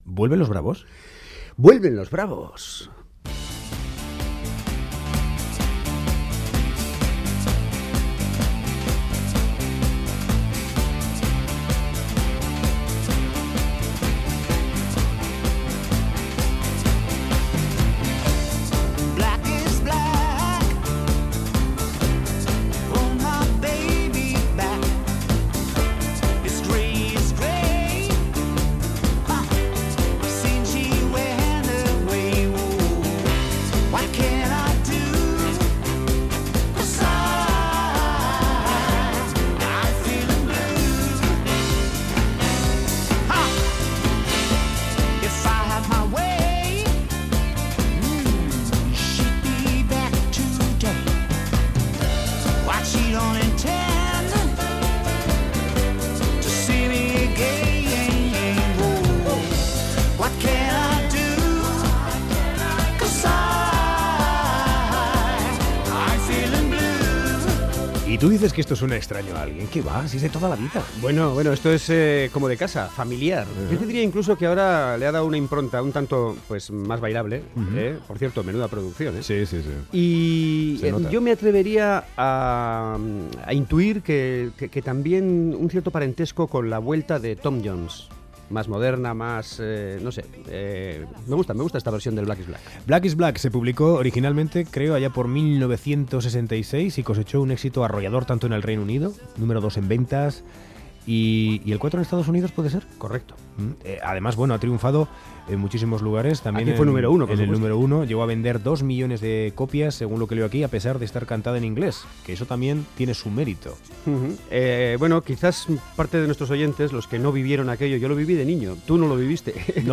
Ahora, al cumplir medio siglo, lo publican en castellano con otros dos temas inéditos. Conversamos con su carismático cantante Mike Kennedy.